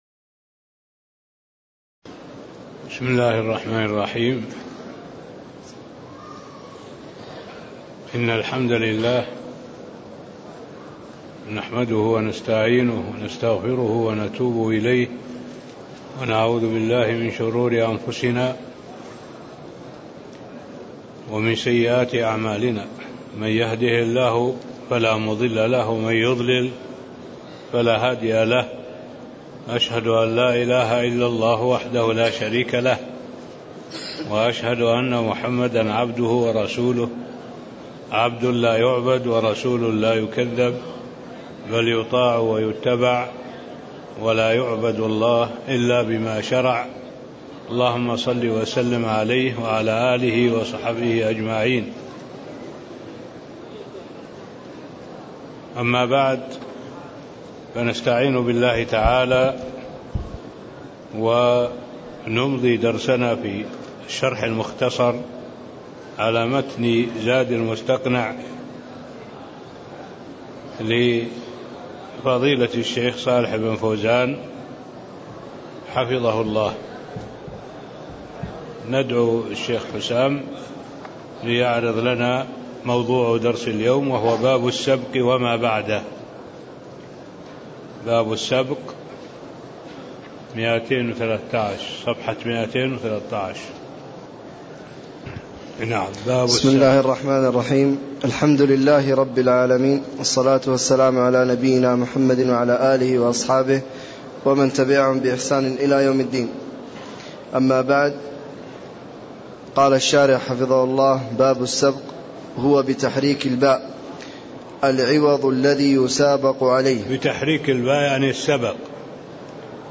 تاريخ النشر ٢٢ ربيع الثاني ١٤٣٥ هـ المكان: المسجد النبوي الشيخ: معالي الشيخ الدكتور صالح بن عبد الله العبود معالي الشيخ الدكتور صالح بن عبد الله العبود باب السبق (14)